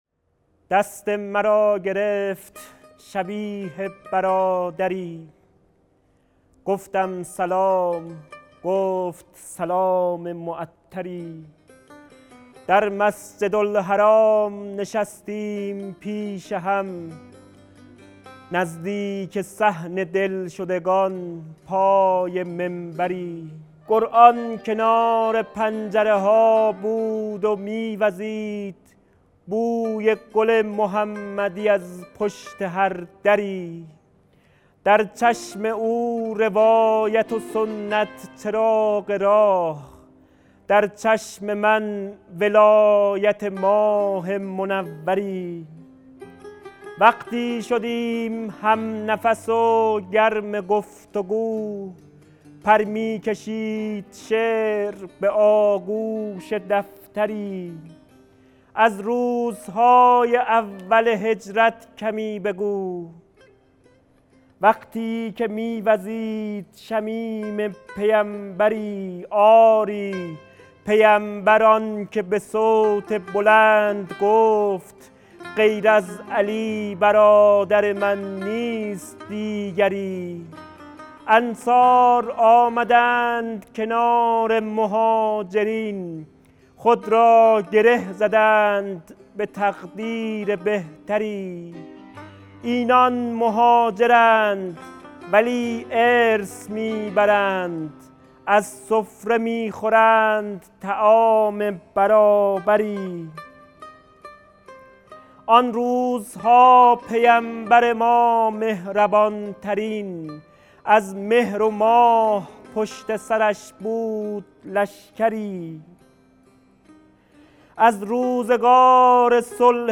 شعرخوانی | دست مرا گرفت شبیه برادری